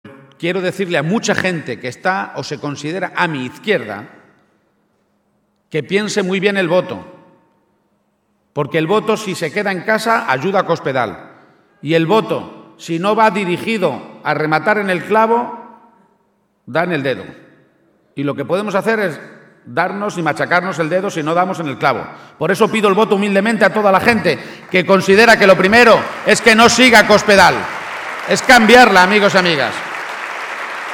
El candidato del PSOE a la Presidencia de Castilla-La Mancha, Emiliano García-Page, aseguraba hoy en Talavera de la Reina (Toledo) que “esta tierra no necesita un cambio histórico, ese ya lo hicimos nosotros, sino ir todos a una el día 24 a cambiarla”.